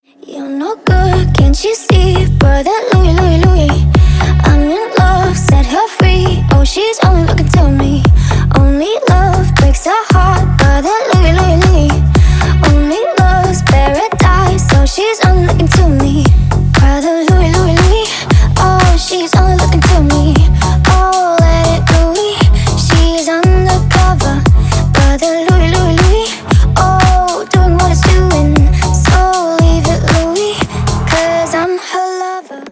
Популярный ремикс на вызов